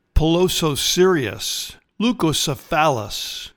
Pronounciation:
Pi-lo-so-SER-e-us leu-co-ce-PHAL-us